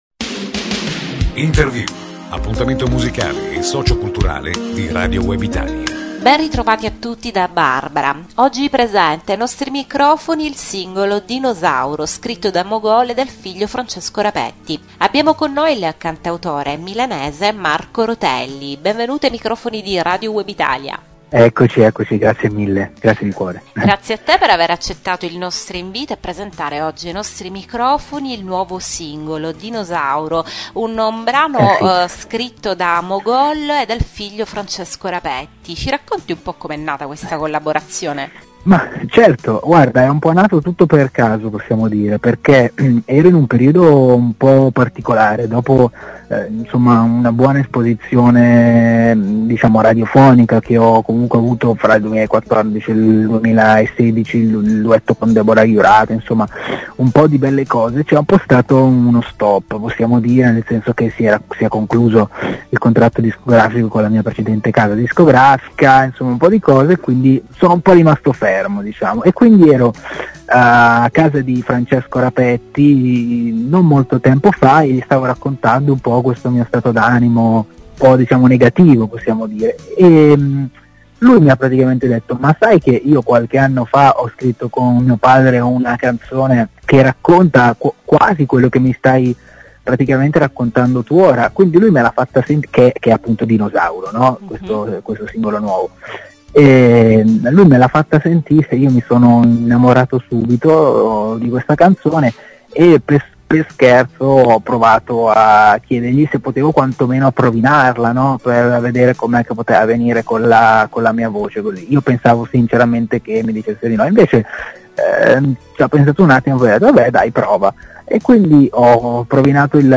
Intervista On Air giovedì 8 novembre ore 18.10 Podcast Radio Web Italia https